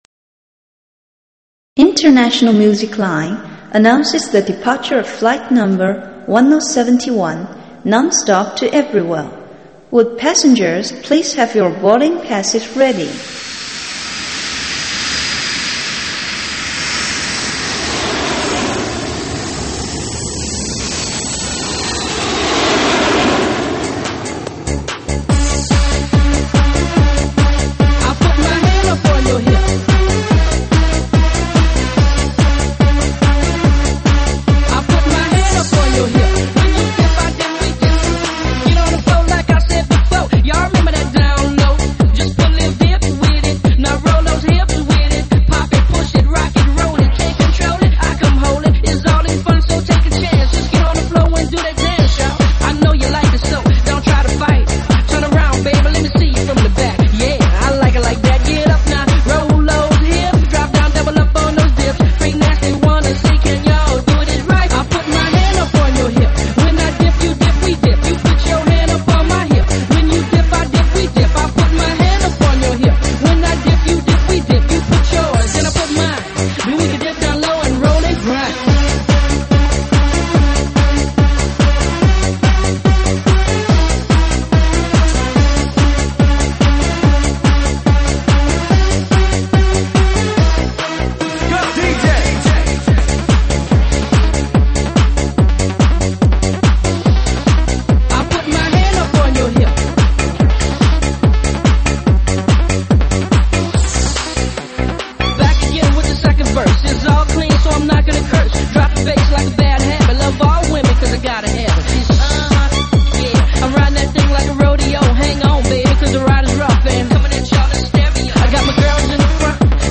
经典 抖音 伤感情歌 暖场